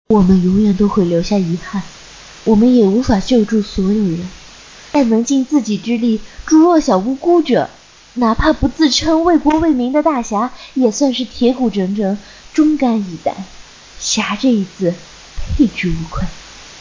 甜美俏皮